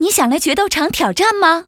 文件 文件历史 文件用途 全域文件用途 Choboong_tk_02.ogg （Ogg Vorbis声音文件，长度0.0秒，0 bps，文件大小：23 KB） 源地址:游戏语音 文件历史 点击某个日期/时间查看对应时刻的文件。